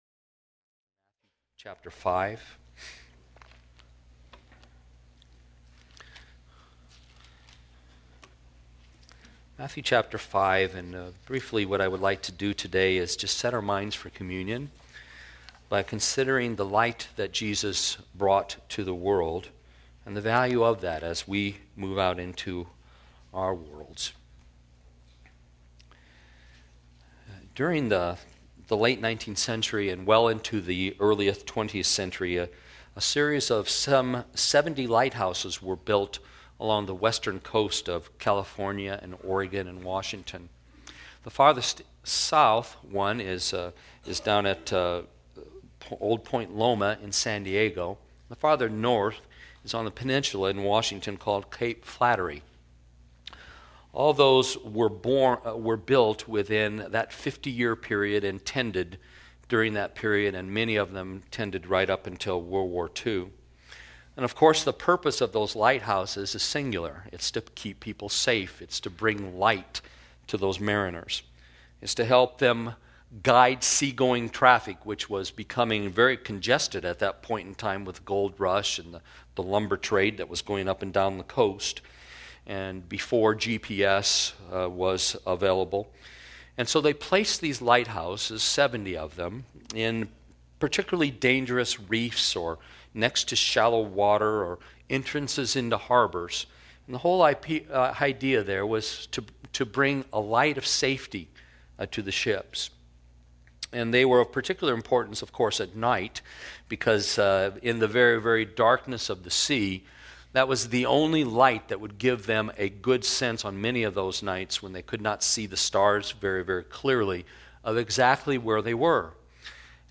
Church of the Canyons - Sermons - Santa Clarita - Evangelical Free